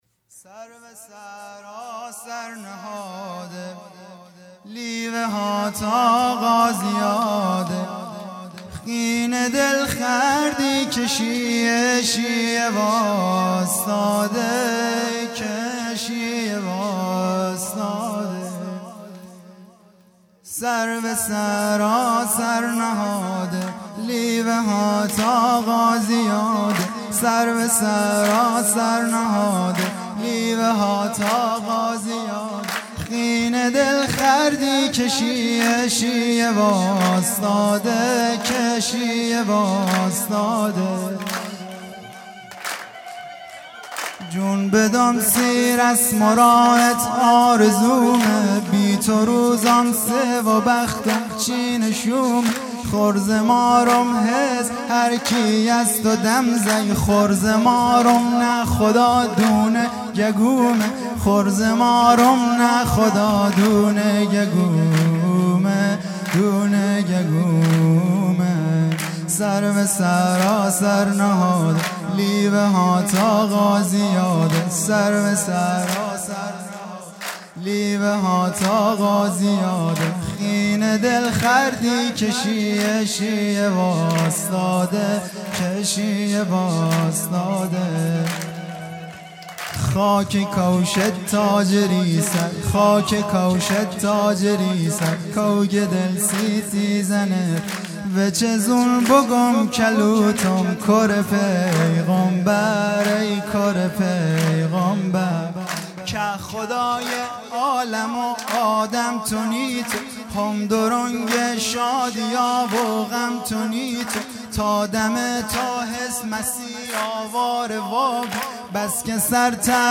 ولادت پیامبر (ص) و امام صادق (ع) | ۳ آذر ۱۳۹۷